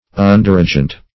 Underagent \Un"der*a`gent\, n. A subordinate agent.